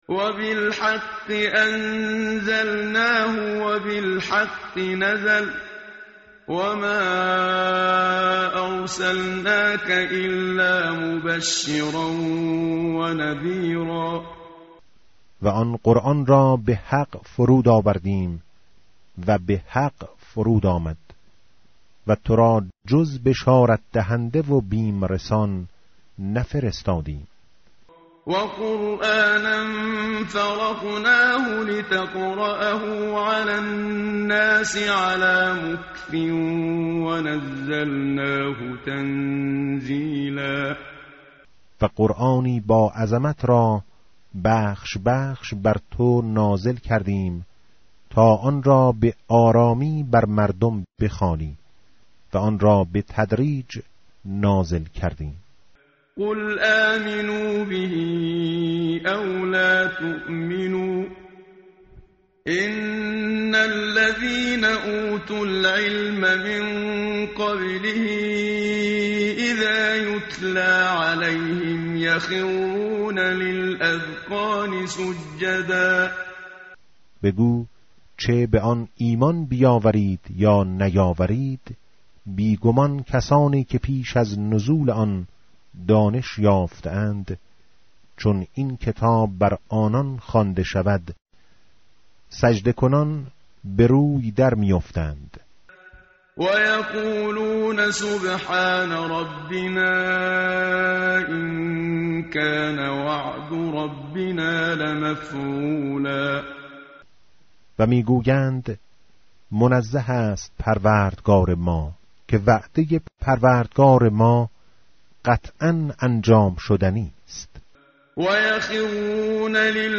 tartil_menshavi va tarjome_Page_293.mp3